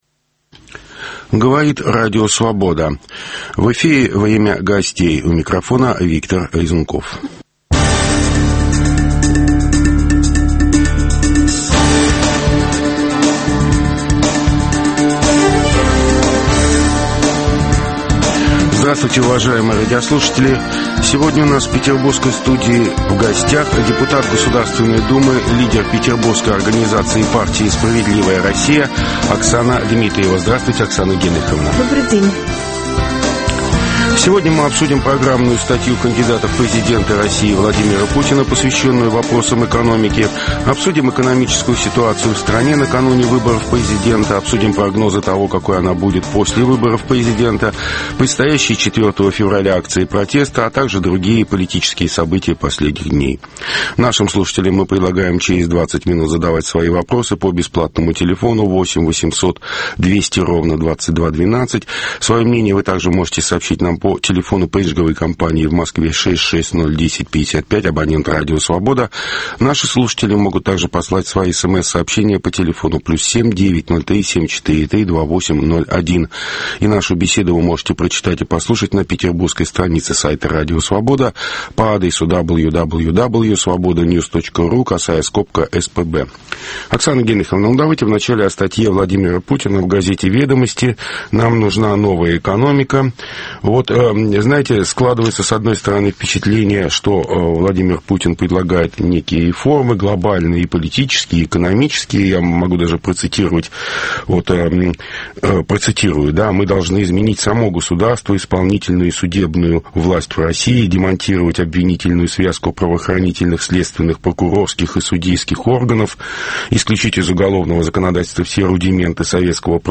Реально ли создать в России "новую экономику", предложенную Владимиром Путиным, если он станет президентом? Обсуждаем с депутатом Госдумы, лидером петербургской организации партии "Справедливая Россия" Оксаной Дмитриевой.